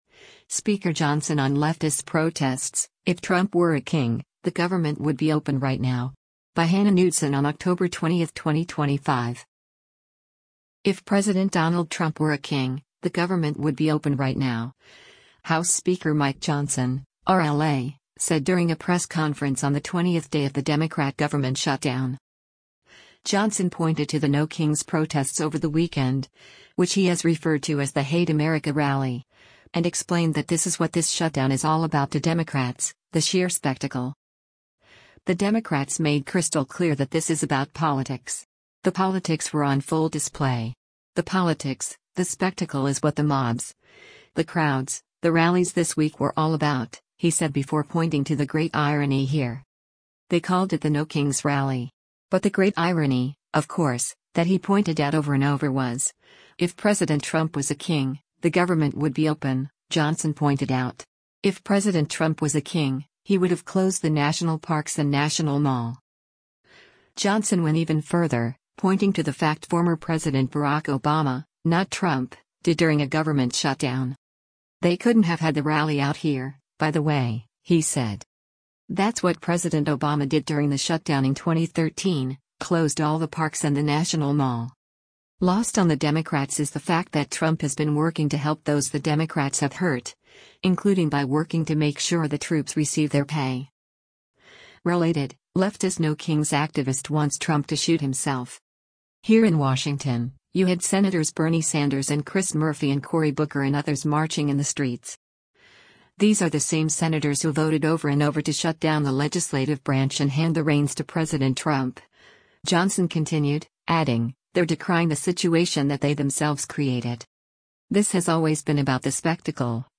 If President Donald Trump were a king, the government would be opened right now, House Speaker Mike Johnson (R-LA) said during a press conference on the 20th day of the Democrat government shutdown.